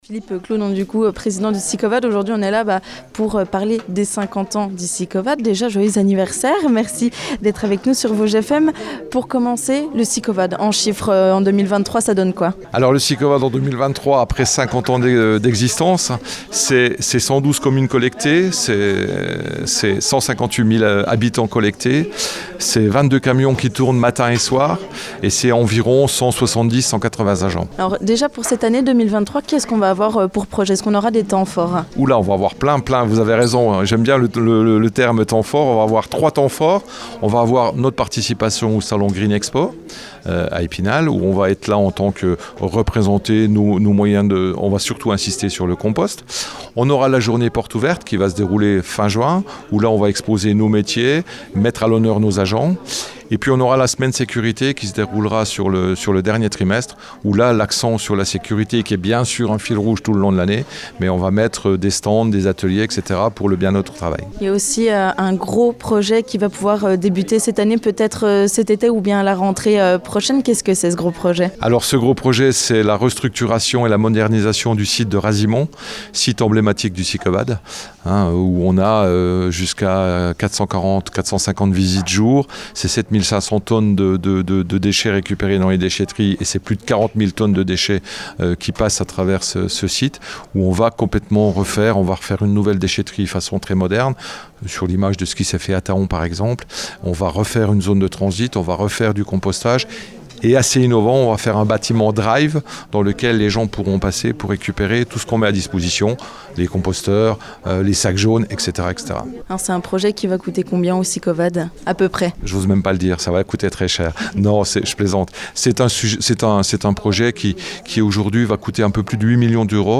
On en parle avec Philippe Claudon, le président du SICOVAD !